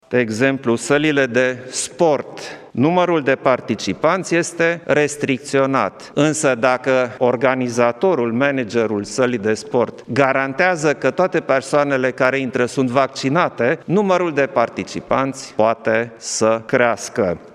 Anunțul a fost făcut de președintele Klaus Iohannis după o ședință cu membri Guvernului și mai mulți specialiști din sănătate:
13mai-19-Iohannis-salile-de-sport.mp3